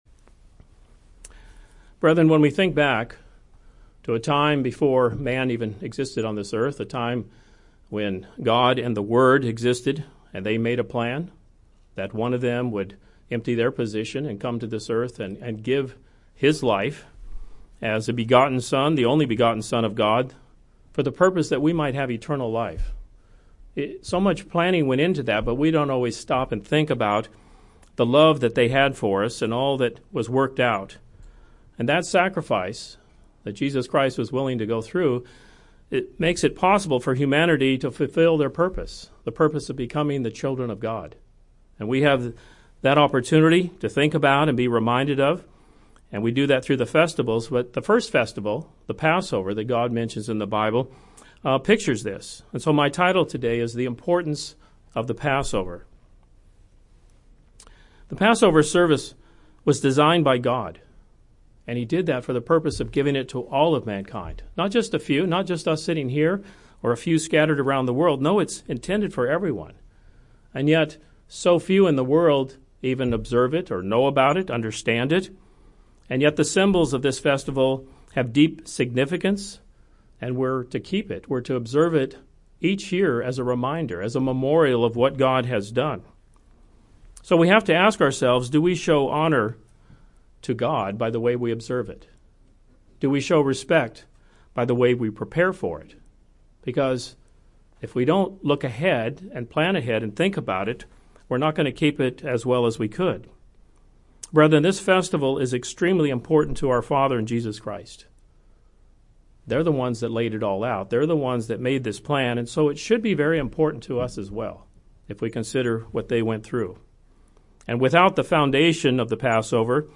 This sermon reviews four Passovers and the various changes that God instructed over time. The Festival of the Passover was designed by God and the symbols have deep significance.